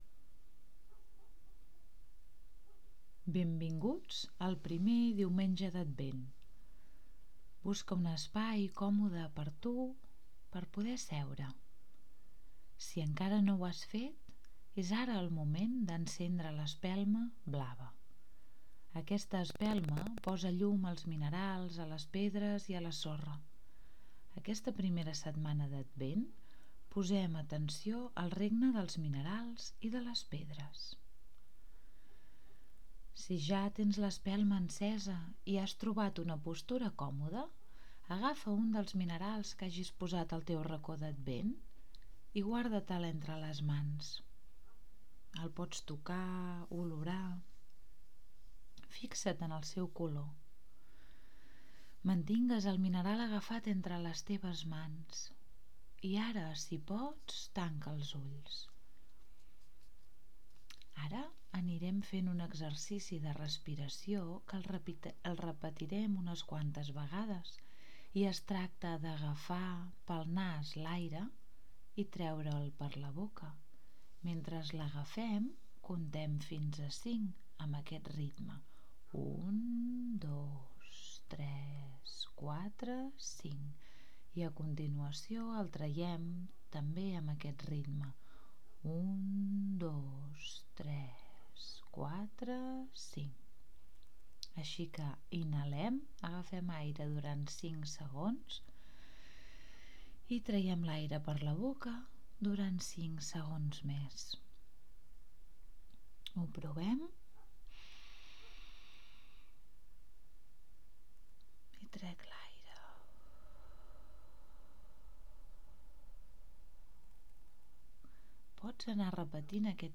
AUDIO DE LA MEDITACIÓ Regne Mineral: Si volem o necessitem una guia per fer una meditació durant el ritual del diumenge, podem escoltar plegats una meditació d’uns 5 minuts aproximadament. Després de la meditació a l’audio hi ha la lectura del poema i d’un conte adaptat.